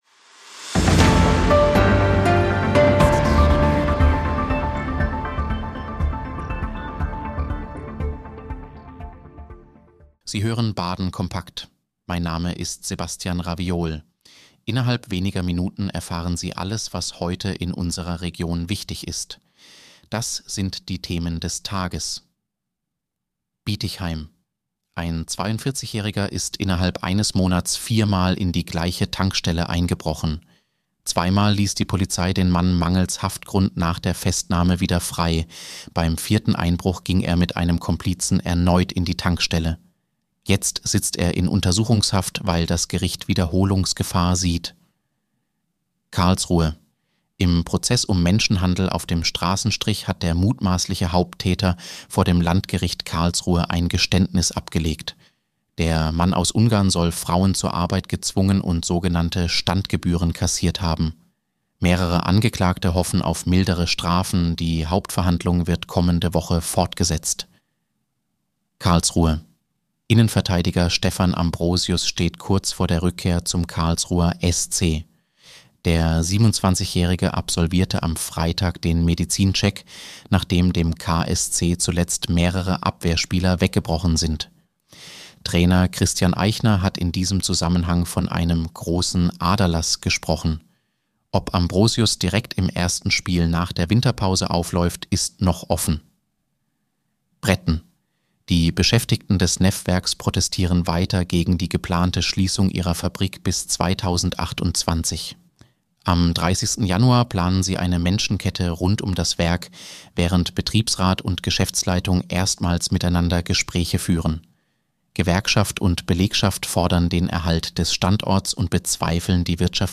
Nachrichtenüberblick Freitag, 16. Januar 2026